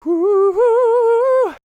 DD FALSET025.wav